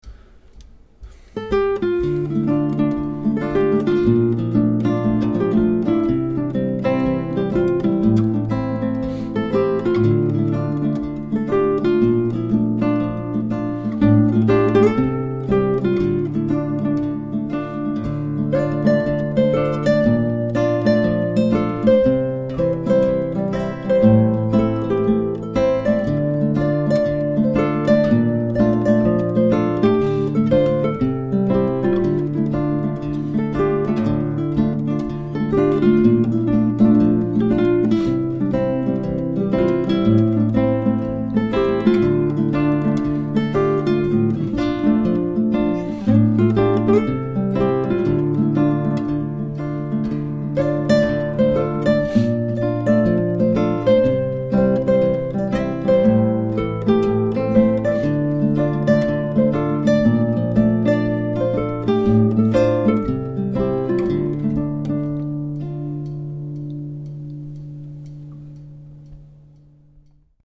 l'enregistrement des deux voix ;